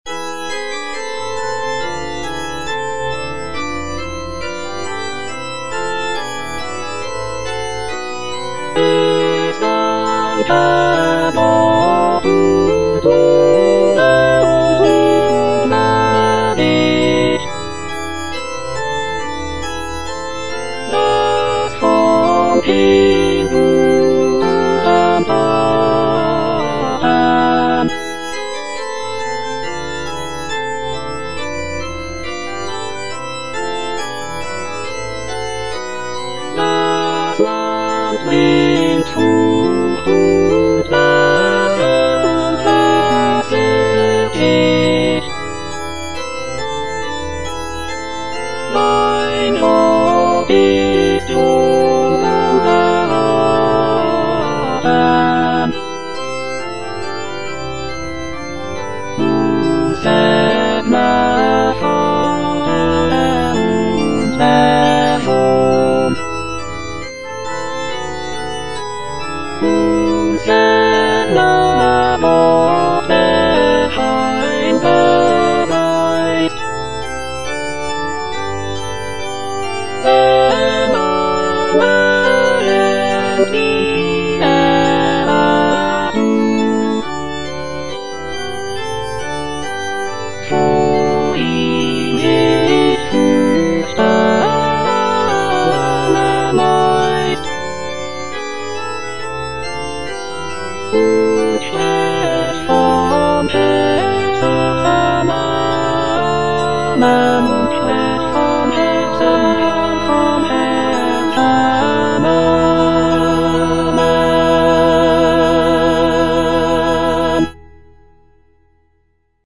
Choralplayer playing Cantata
The work features intricate choral writing, beautiful melodies, and rich orchestration, showcasing Bach's mastery of baroque music composition."